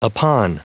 Prononciation du mot upon en anglais (fichier audio)
Prononciation du mot : upon